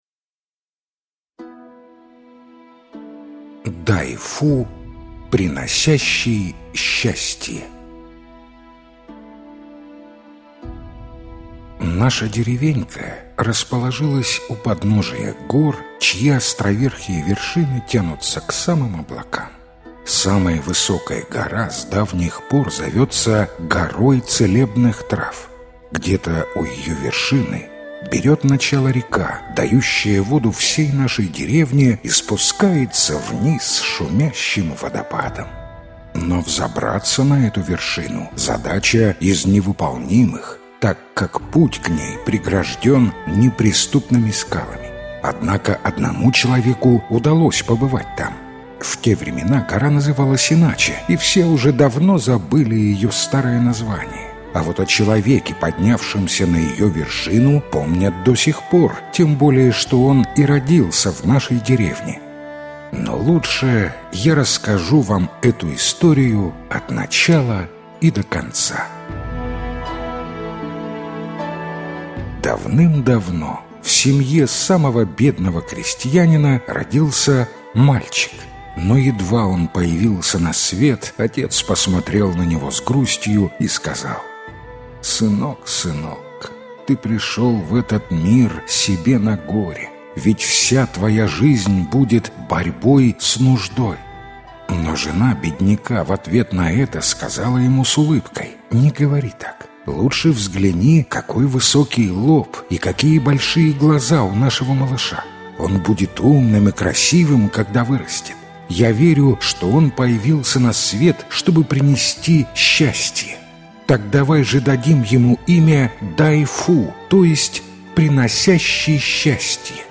Дай-фу-Приносящий счастье - китайская аудиосказка - слушать